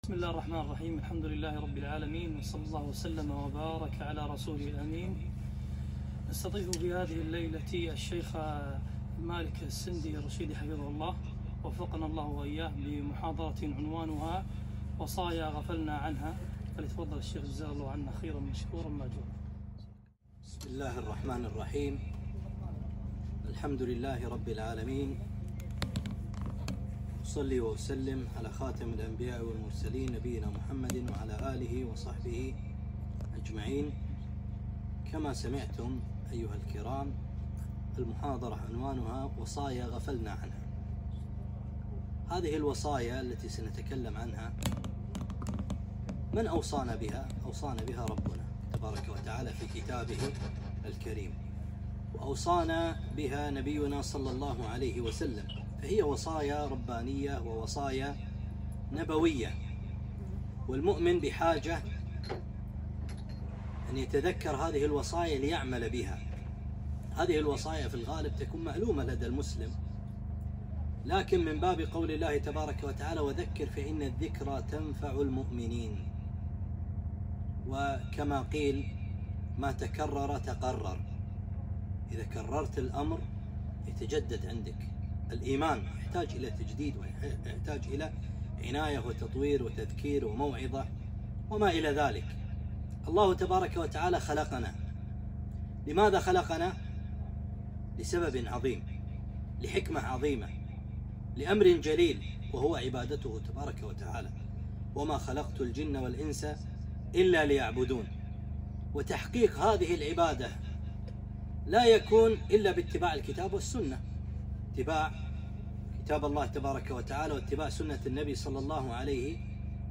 محاضرة - وصايا غفلنا عنها